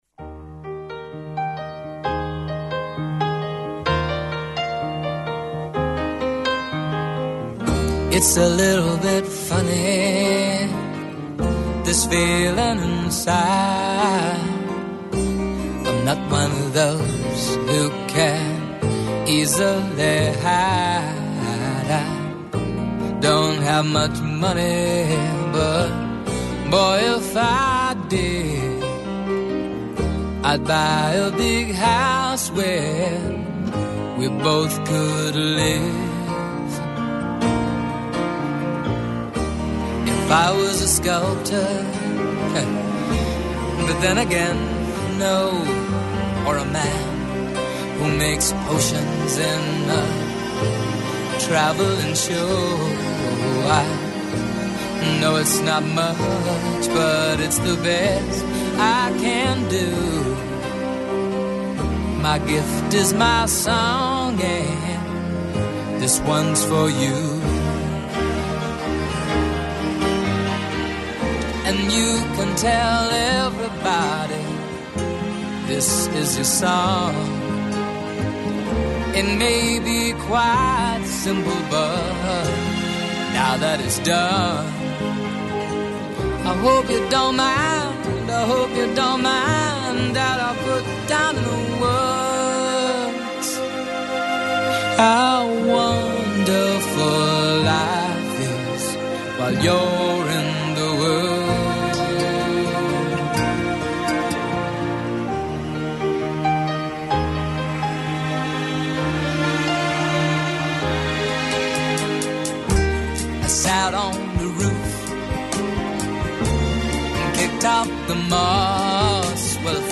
Ακούστε την εκπομπή του Νίκου Χατζηνικολάου στον ραδιοφωνικό σταθμό RealFm 97,8, την Τετάρτη 24 Σεπτεμβρίου 2025.